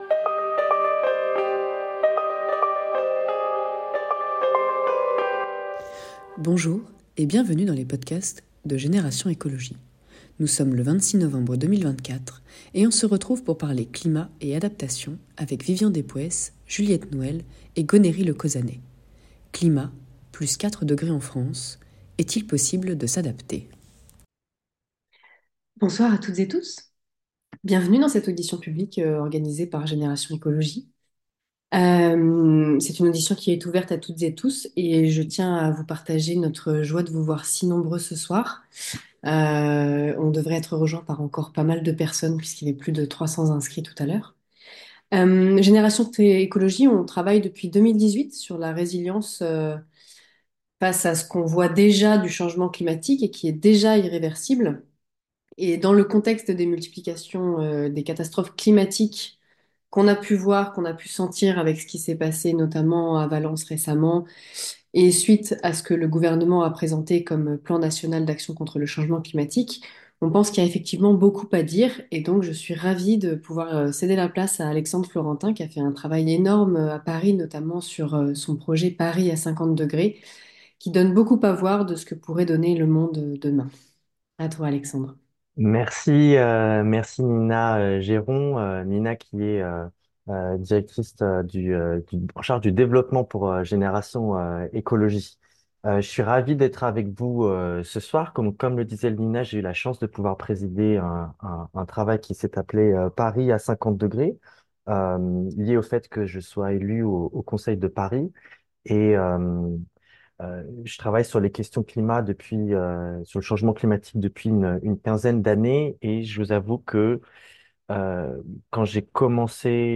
Audition publique